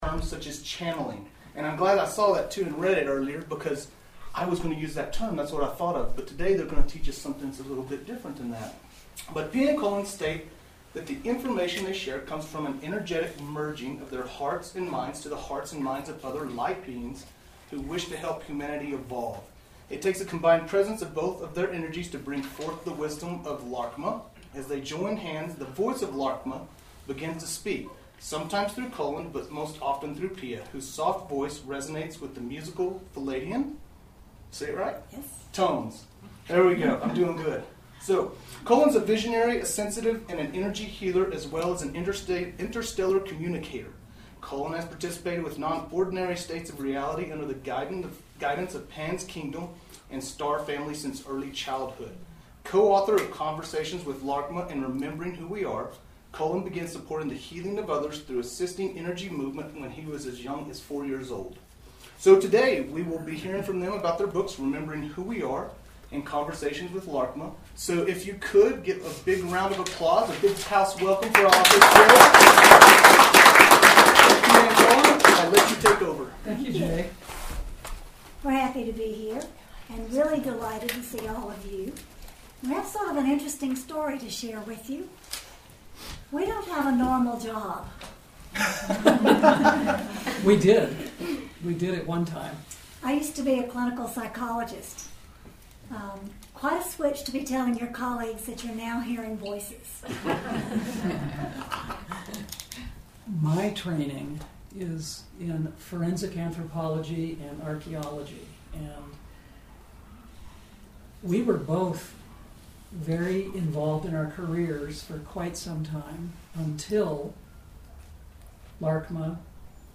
Recording - March 29 - Crystal Dove, Albuquerque